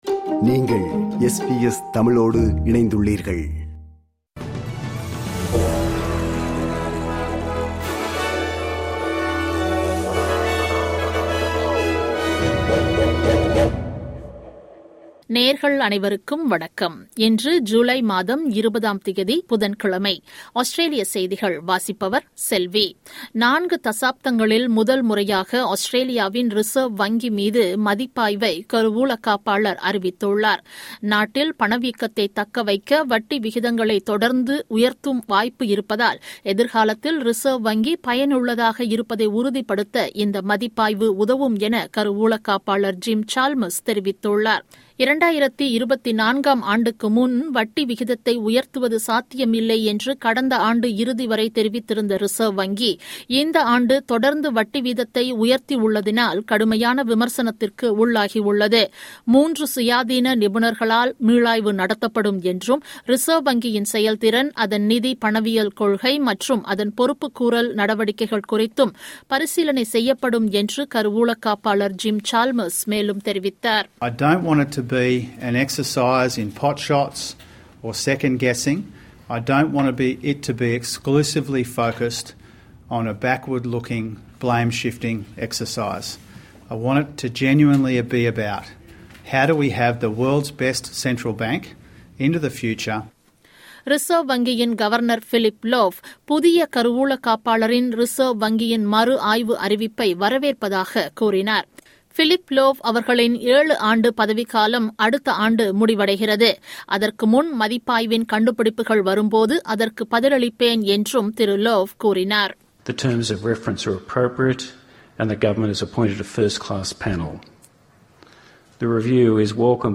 Australian news bulletin for Wednesday 20 July 2022.